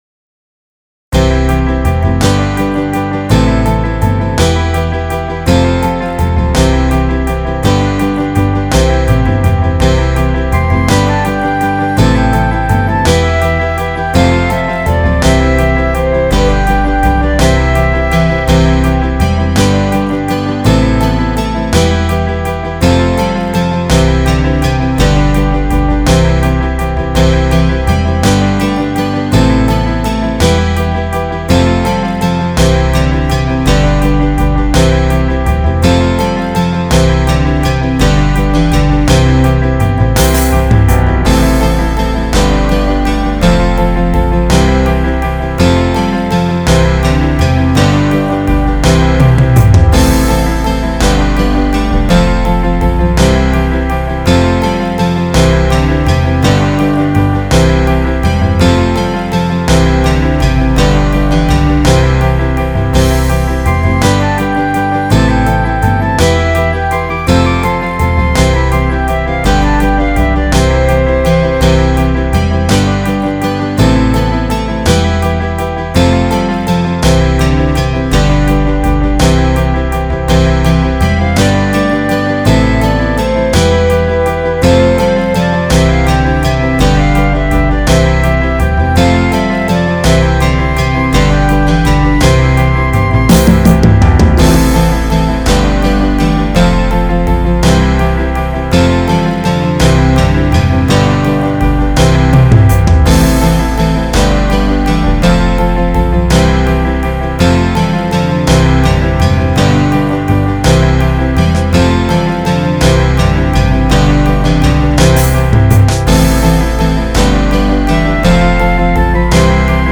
MP3 Accompaniment